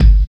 81 DEEP DRUM.wav